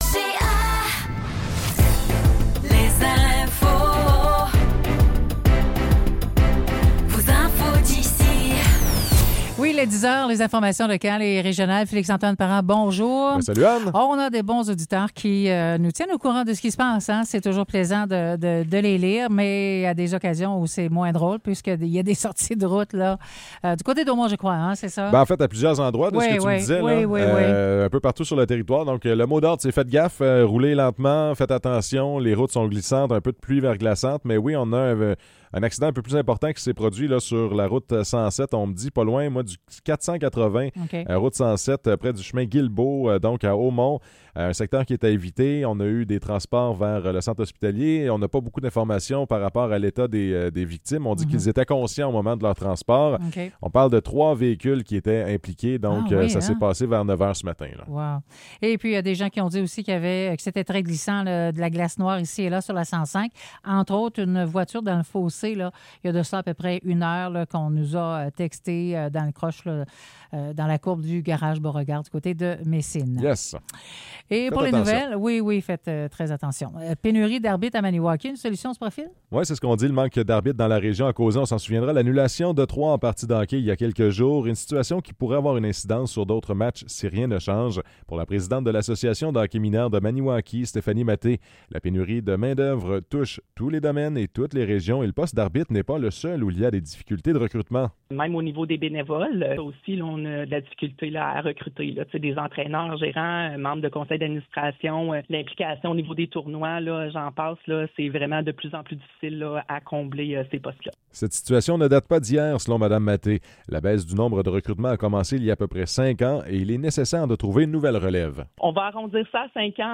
Nouvelles locales - 16 décembre 2024 - 10 h